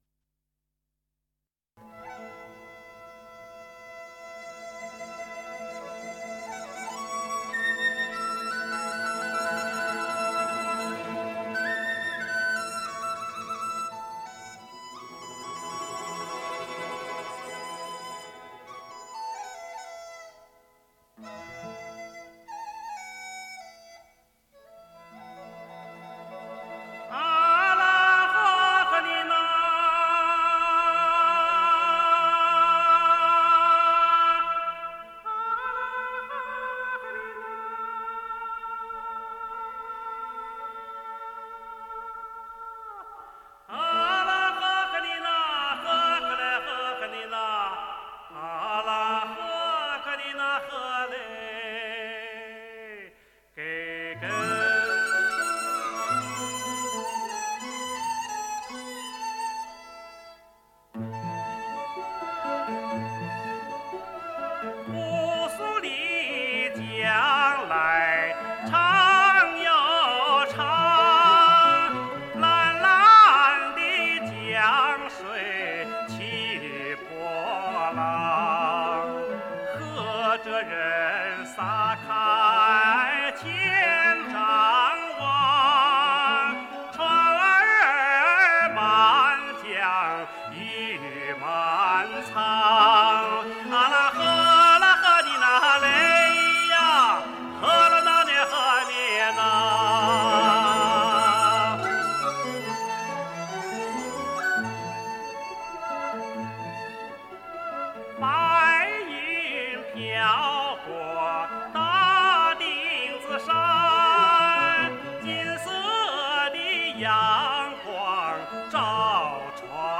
中央人民广播电台原版录音
男高音歌唱家、民歌演唱家
以演唱东北民歌著称。